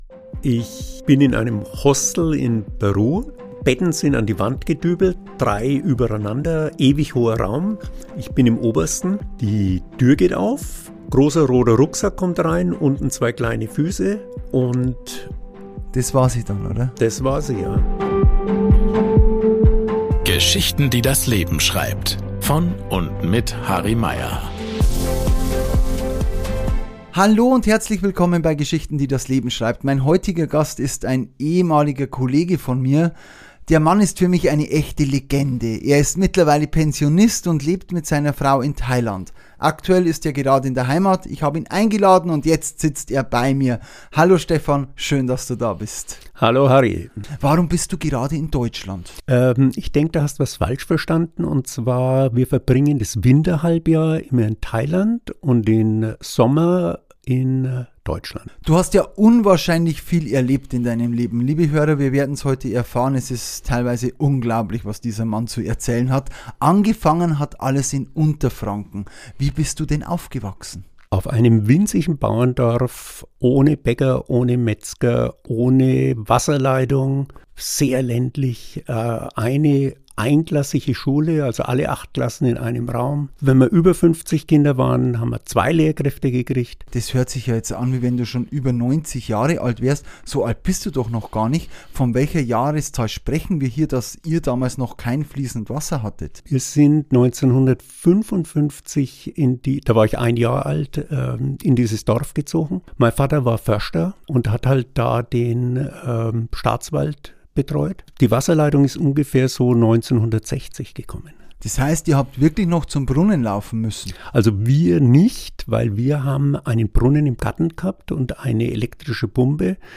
Wir sprechen über seine wilden Jugendjahre, den Weg in den Lehrerberuf, seine Liebe zum Reisen, das Schicksalstreffen mit seiner Frau Nah am Machu Picchu und das Leben als Auswanderer in Thailand. Ein Gespräch voller Anekdoten, Lebensweisheiten und der Erkenntnis, dass das Glück manchmal ganz unverhofft zuschlägt.